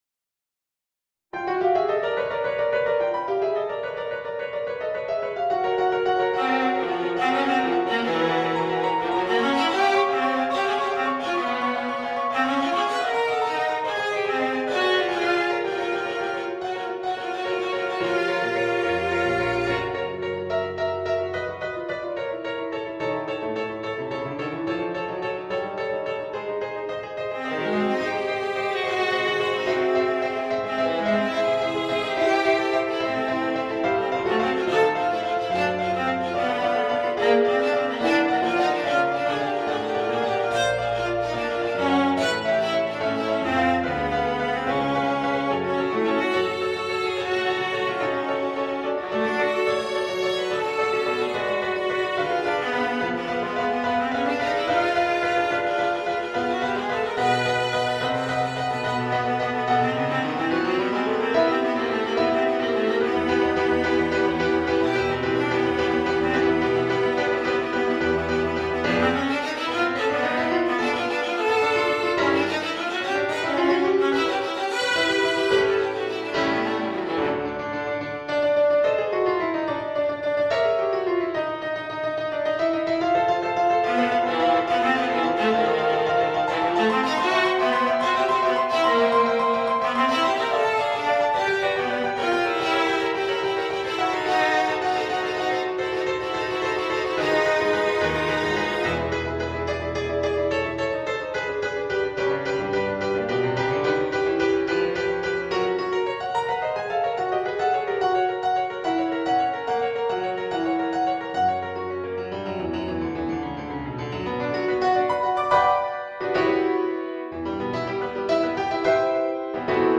（ヴィオラ+ピアノ）